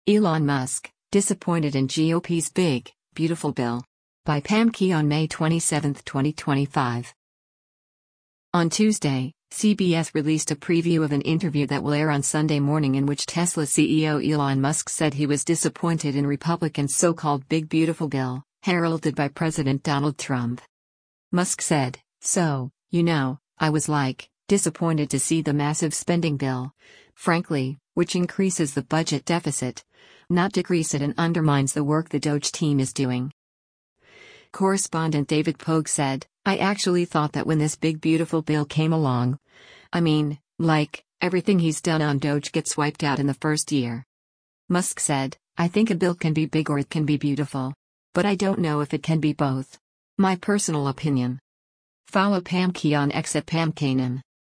On Tuesday, CBS released a preview of an interview that will air on “Sunday Morning” in which Tesla CEO Elon Musk said he was disappointed in Republican’s so-called “big beautiful bill,” heralded by President Donald Trump.